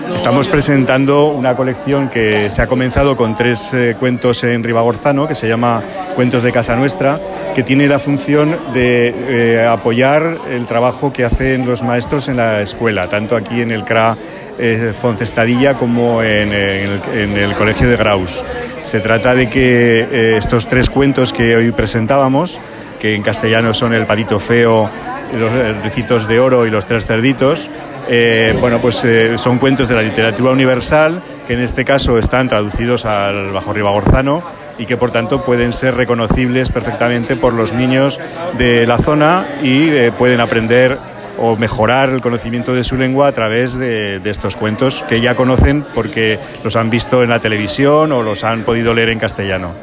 Audio del director de Política Lingüística del Gobierno de Aragón, Ignacio López Susín: